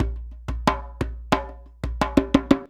089DJEMB08.wav